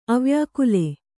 ♪ avyākule